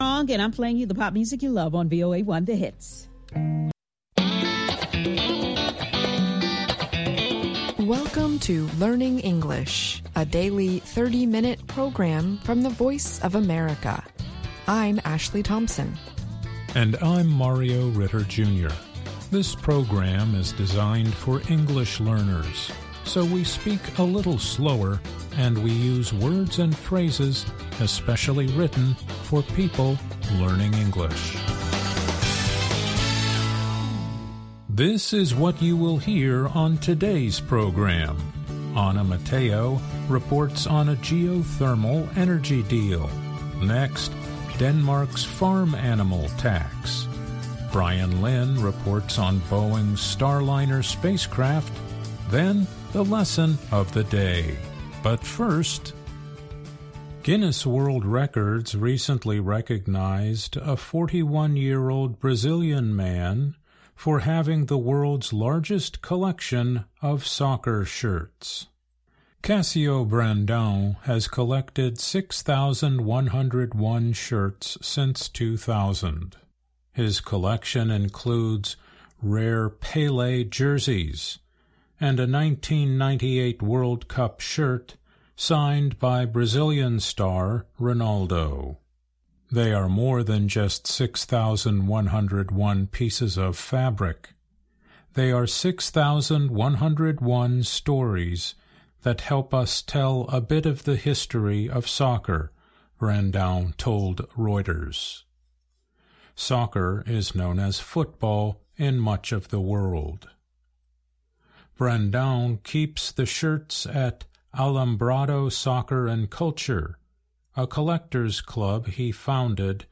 Learning English uses a limited vocabulary and are read at a slower pace than VOA's other English broadcasts.